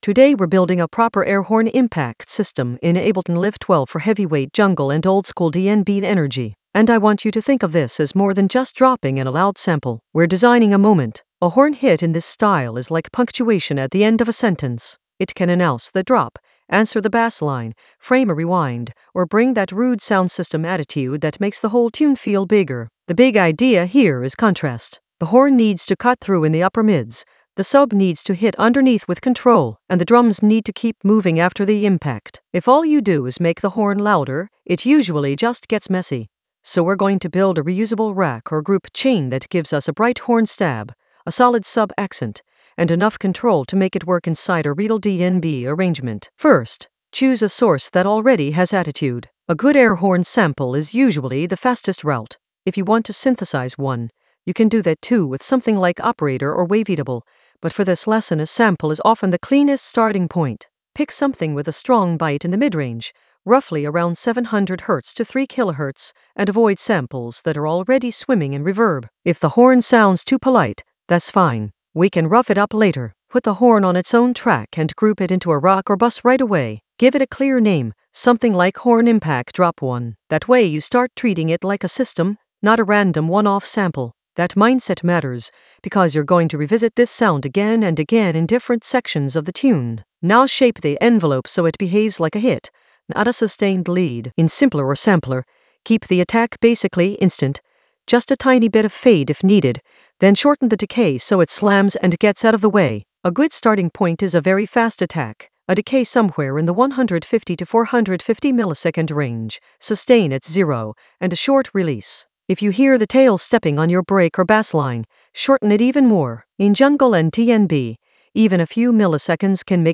An AI-generated intermediate Ableton lesson focused on System for air horn hit for heavyweight sub impact in Ableton Live 12 for jungle oldskool DnB vibes in the FX area of drum and bass production.
Narrated lesson audio
The voice track includes the tutorial plus extra teacher commentary.